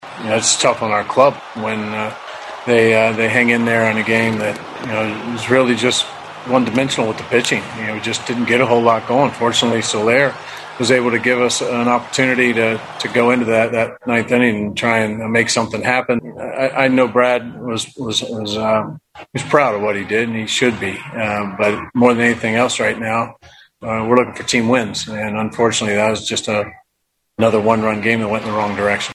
Royals manager Mike Matheny.